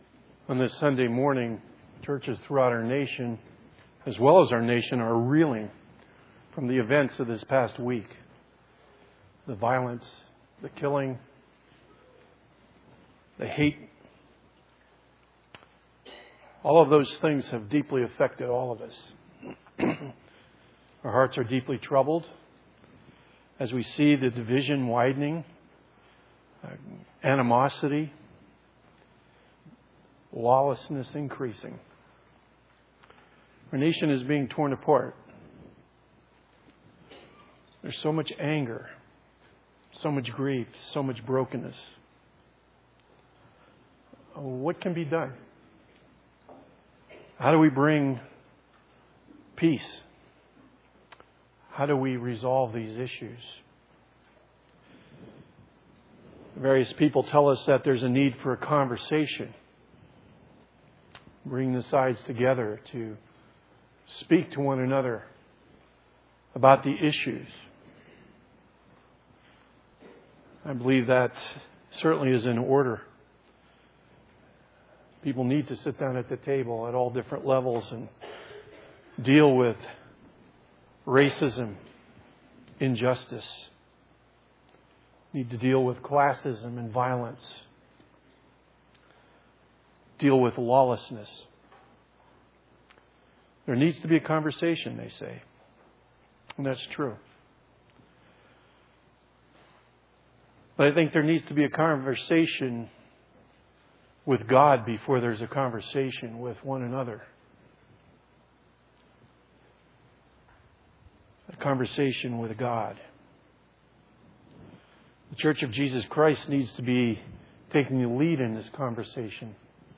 A Collection of 2016 Sermons from Windsor Baptist Chruch